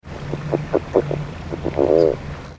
The advertisement call of the California Red-legged Frog can be described as a weak series of 5 - 7 notes, sounding like uh-uh-uh-uh-uh, lasting 1 - 3 seconds that do not have a lot of volume.
The following sounds were all recorded on a sunny morning in early March at the edge of a pond in Monterey County.
Birds, wind noise, and water flowing into the pond from a small seep can be heard in the background.
Sound This is a 2 second recording of one advertisement call of a single frog, which includes the growl at the end that is characteristic of this species. (Compare to Rana aurora.)
rdraytoniishortgrowl2.mp3